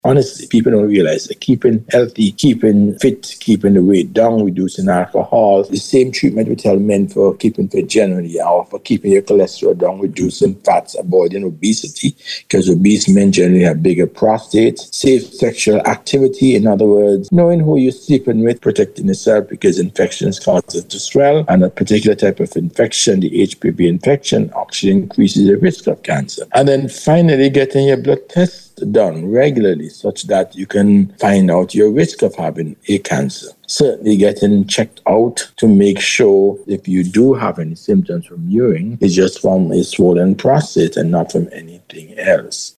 PROSTATE-HEALTH-ADVICE.mp3